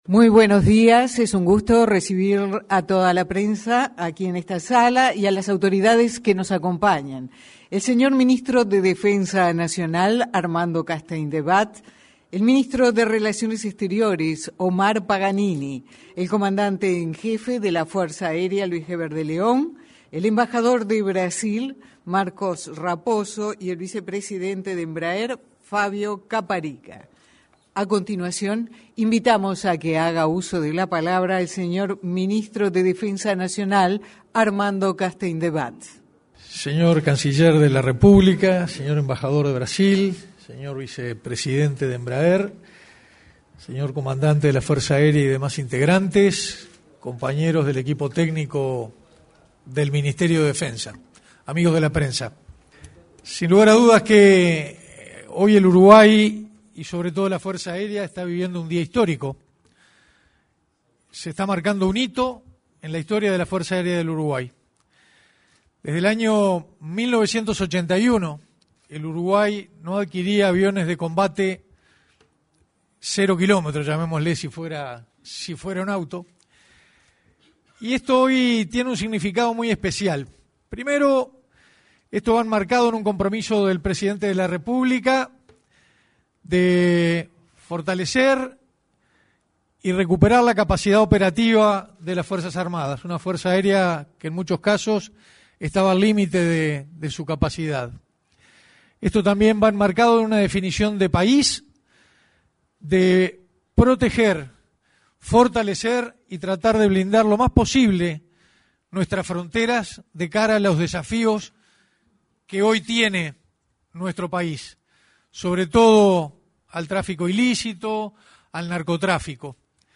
Conferencia de prensa relativa a compra de seis aviones A-29 Super Tucano
Conferencia de prensa relativa a compra de seis aviones A-29 Super Tucano 28/08/2024 Compartir Facebook X Copiar enlace WhatsApp LinkedIn Este miércoles 28, se realizó, en la sala de prensa de la Torre Ejecutiva, una conferencia de prensa con motivo de la compra de seis aviones A-29 Super Tucano. Participaron el ministro de Defensa Nacional, Armando Castaingdebat; su par de Relaciones Exteriores, Omar Paganini, y el comandante en jefe de la Fuerza Aérea Uruguaya, Luis De León.